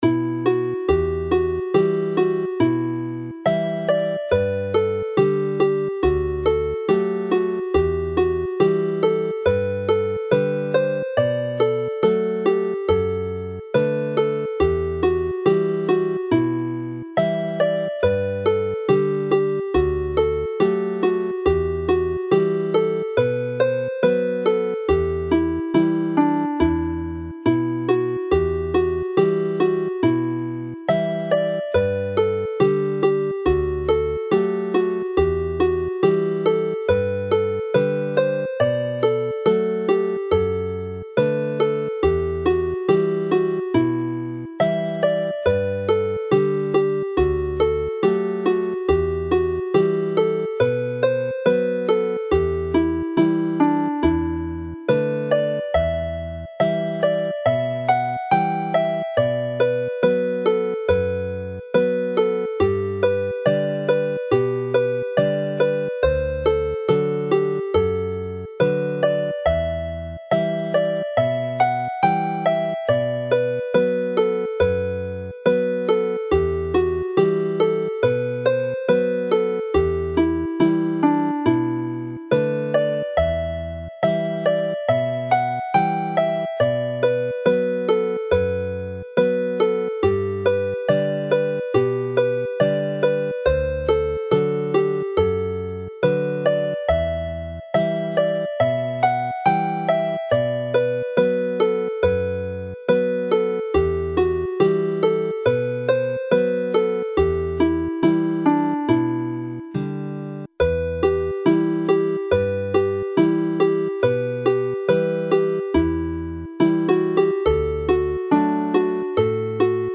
Play the set very slowly